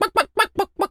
pgs/Assets/Audio/Animal_Impersonations/chicken_cluck_bwak_seq_09.wav at master
chicken_cluck_bwak_seq_09.wav